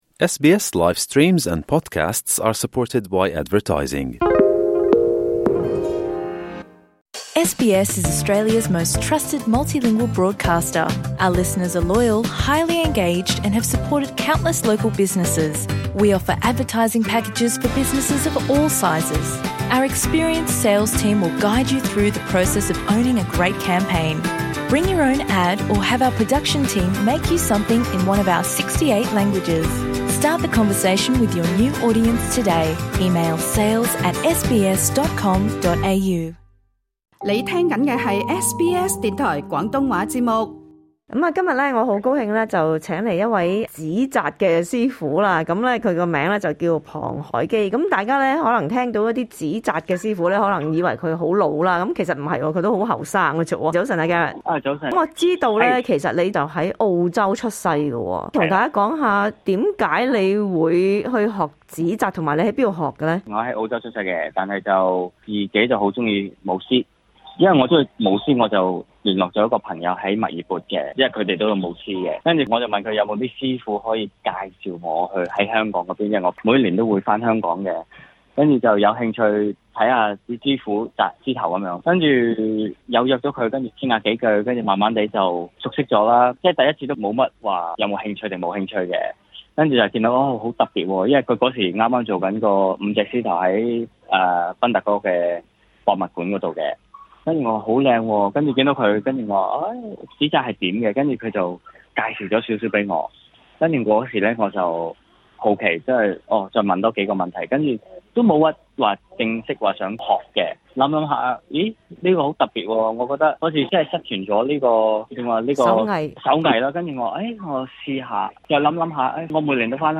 想要知道答案， 請大家收聽這節訪問。